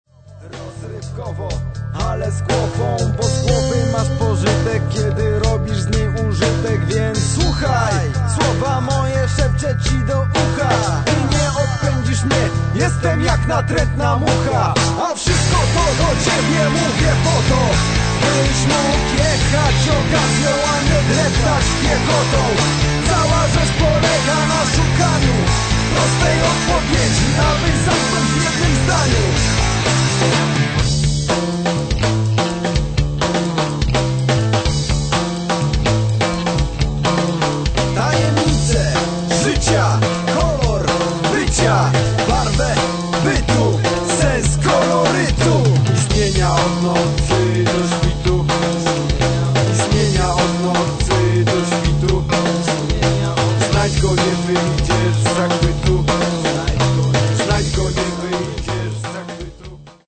hardcore, hiphop, funk